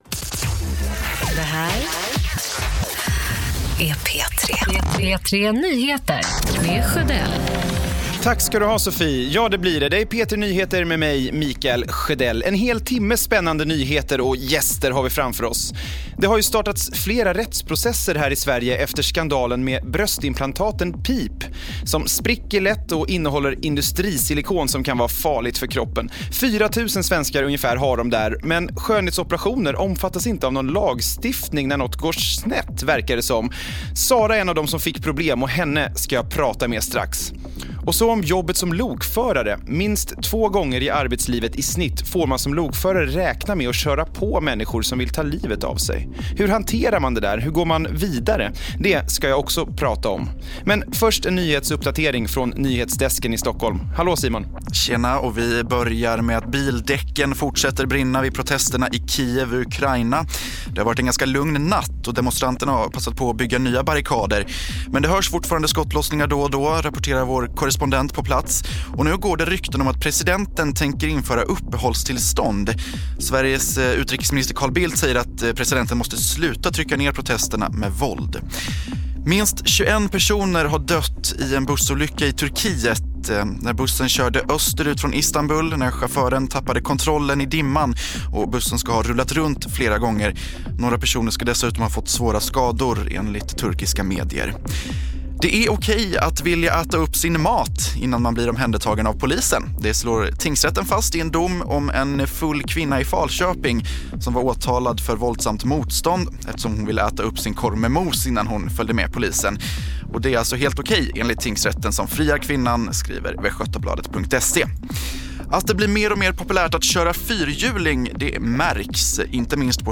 Veckans torsdagsgäst i P3 Nyheter var ingen mindre än Torsbys stolthet Emma Dahlström.
Intervjun med Emma börjar 18:45